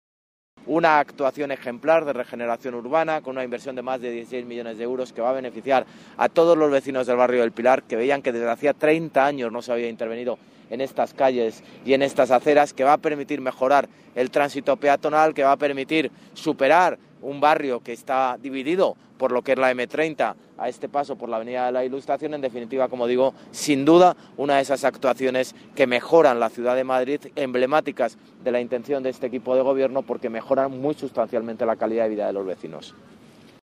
Nueva ventana:Declaraciones del alcalde, José Luis Martínez-Almeida, tras su visita a las obras que se han llevado a cabo en la avenida de la Ilustración